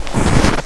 STEPS Snow, Walk 23.wav